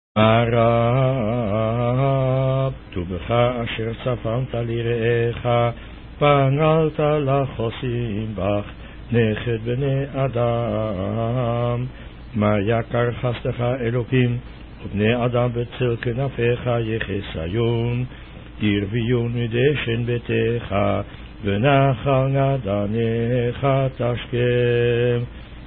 On particular occasions, a special Hashcaba (memorial-prayer) is recited in the memory of our ancestors who died at the Auto Da fé (burning at the stake) at the hands of the Spanish and Portuguese Inquisitions.